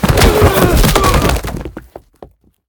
tackle4.ogg